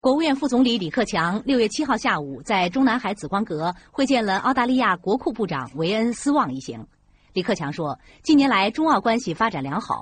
兩位主播的平均語速均為每分鐘300字左右，女主播直覺上語速較快，聽起來較為急促，男主播直覺上語速較慢，聽起來比較舒服，但其實二者語速基本上是一樣的，分別祇在於節奏與停頓。
cctvnews_f.mp3